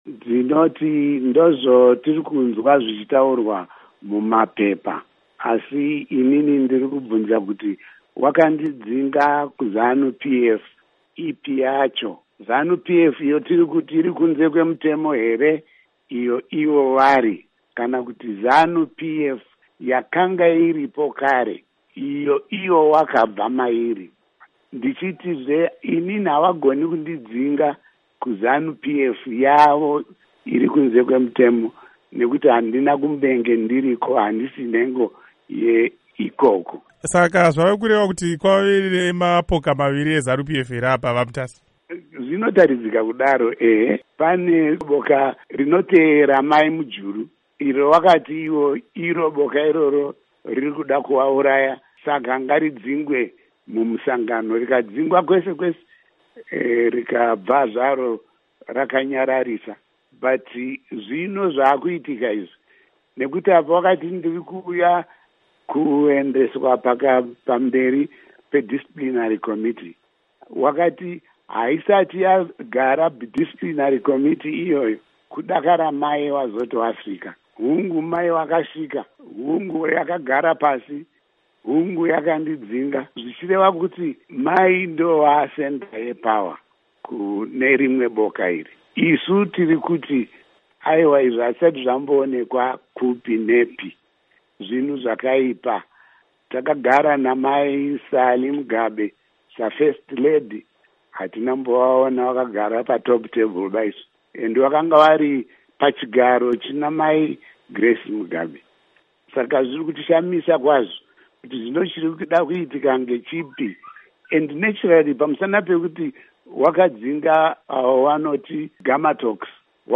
Hurukuro naVaDidymus Mutasa